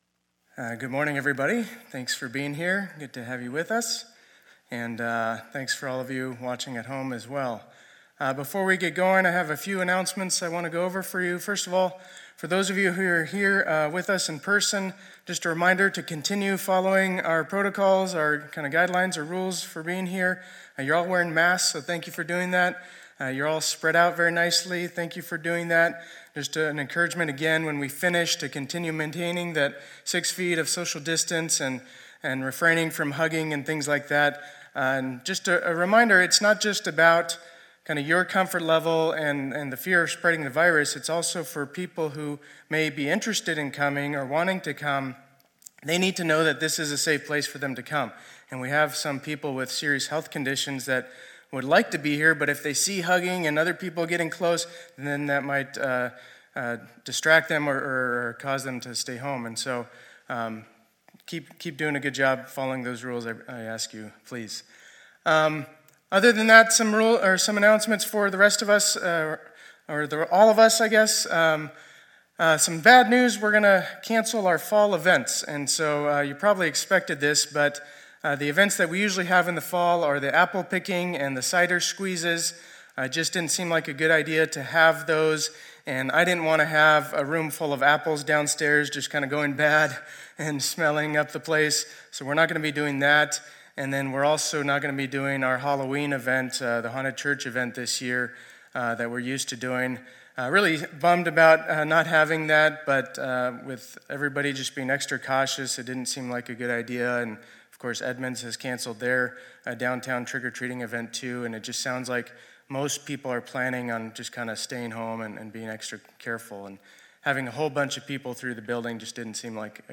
2020-08-27 Sunday Service